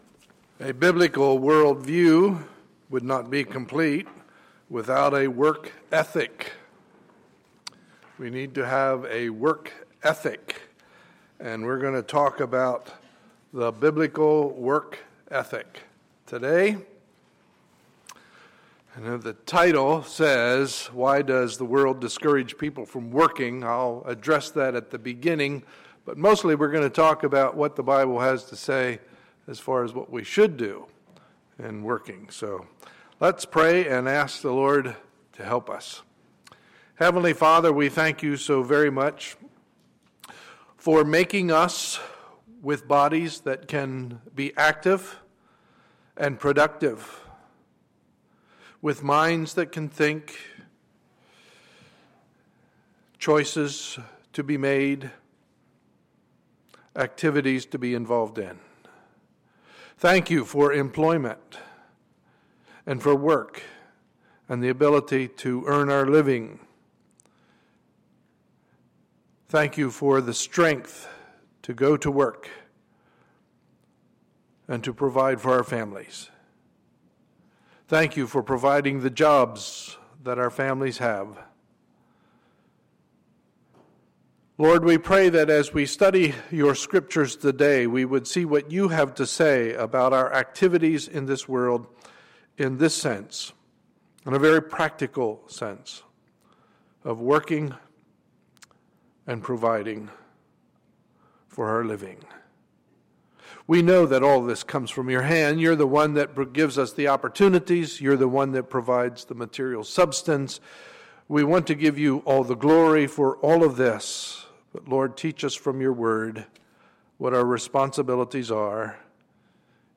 Sunday, August 31, 2014 – Morning Service
Sermons